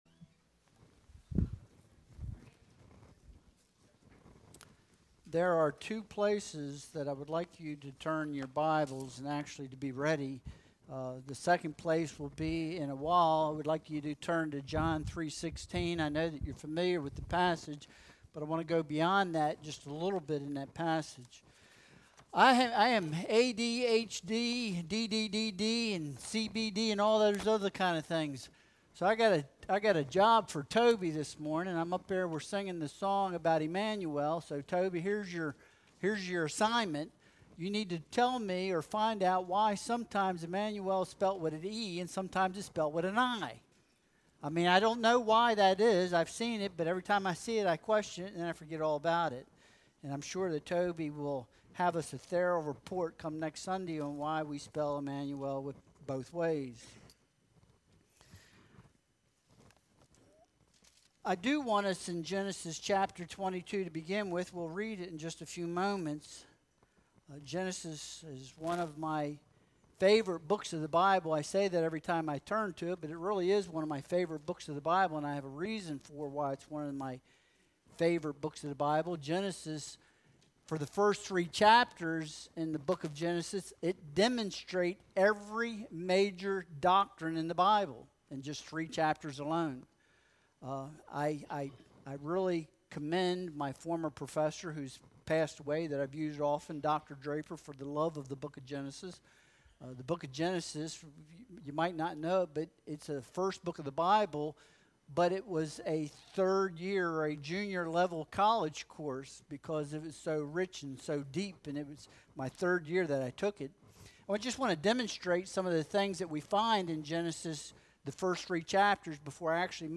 Genesis 22.1-14 Service Type: Sunday Worship Service Download Files Bulletin Topics